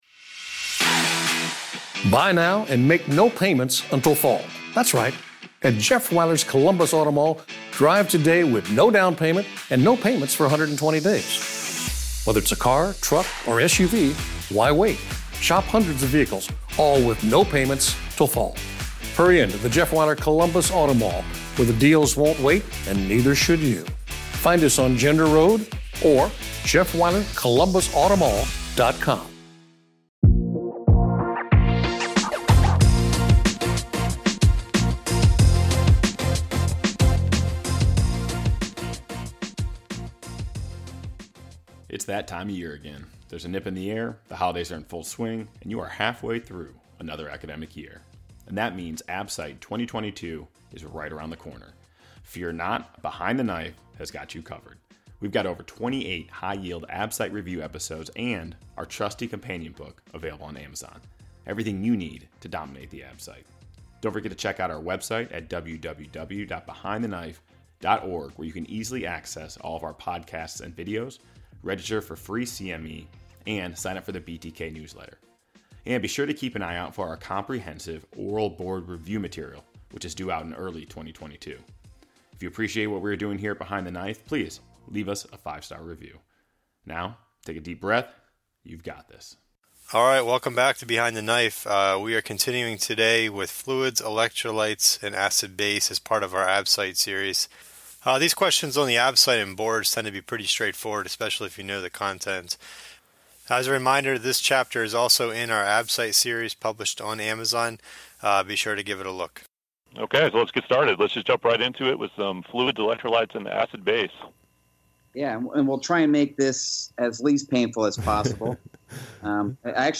*Please note that we apologize for the static in this episode*